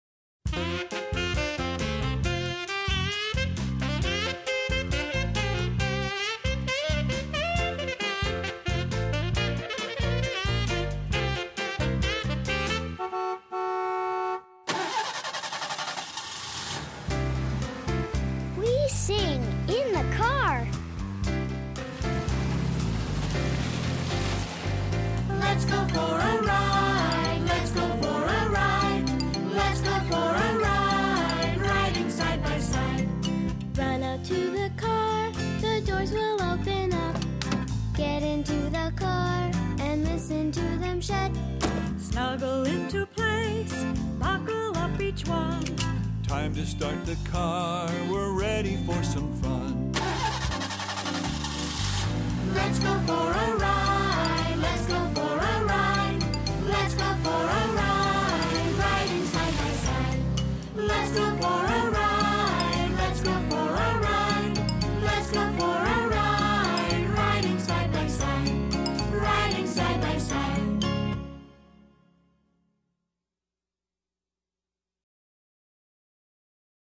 英语童谣